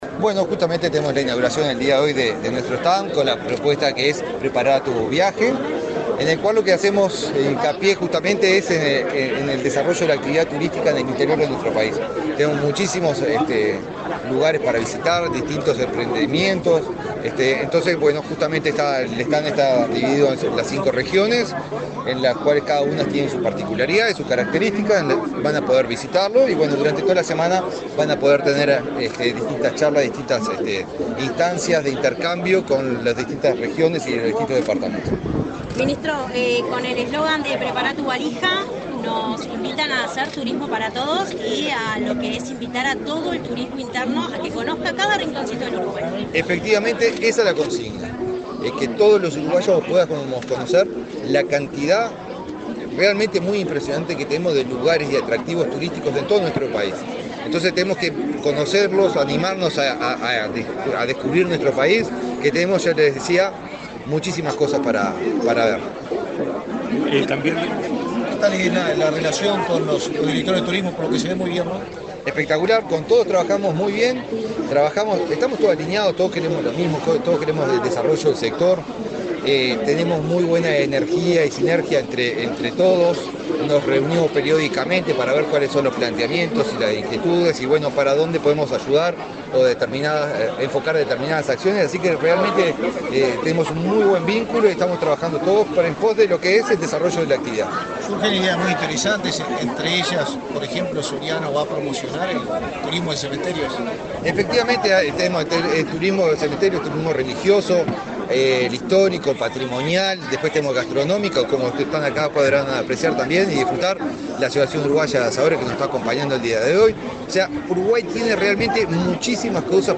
Este viernes 6, el ministro de Turismo, Eduardo Sanguinetti, dialogó con la prensa durante la inauguración del stand de esa cartera en la Expo Prado.